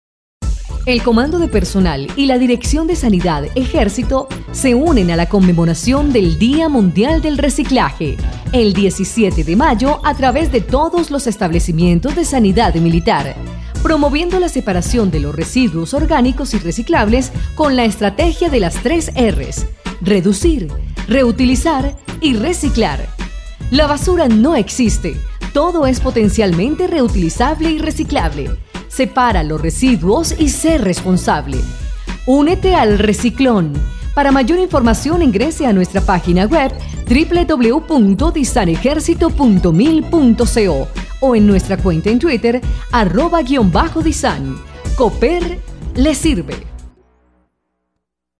Cuña día mundial del reciclaje
CUNA DIA DEL RECICLAJE.mp3